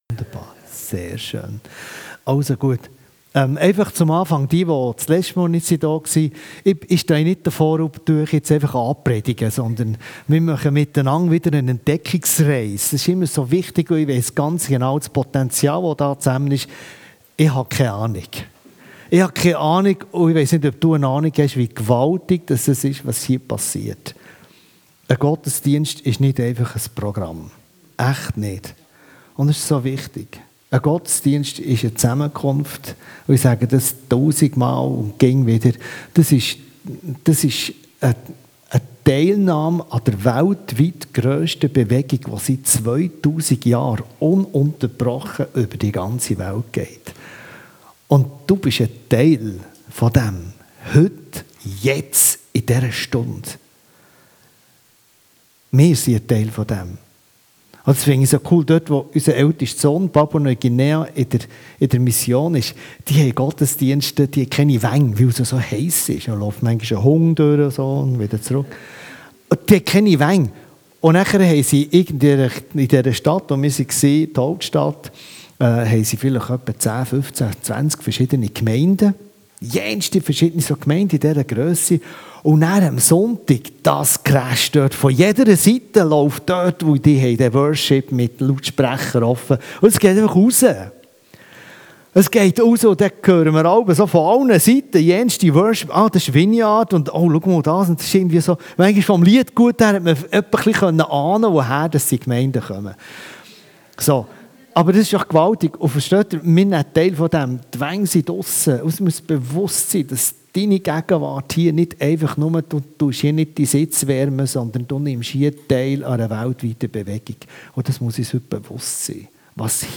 Passage: Apostelgeschichte 10 Dienstart: Gottesdienst Themen